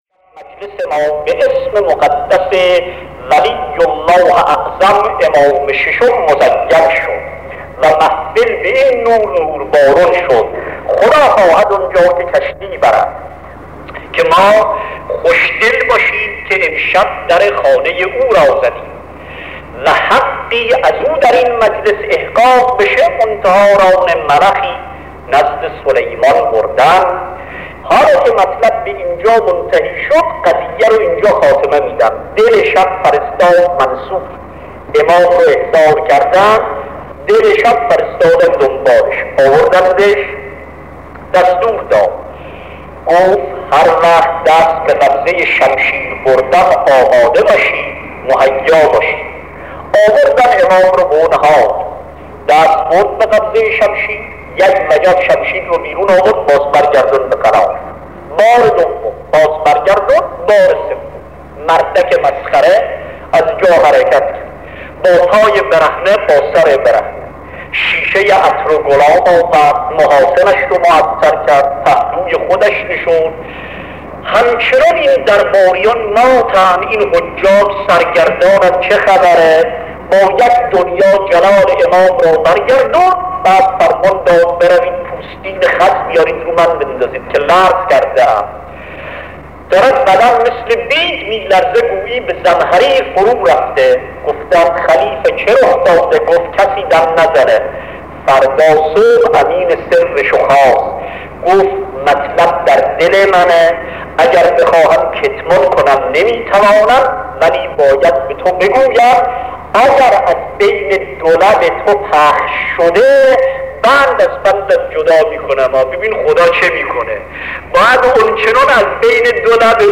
صوت | روضه شنیدنی آیت‌الله وحید خراسانی به مناسبت شهادت امام صادق(ع)
روضه شنیدنی آیتالله وحید خراسانی در کشور کویت به مناسبت شهادت حضرت امام صادق(ع) پس از گذشت نیم قرن منتشر می‌شود.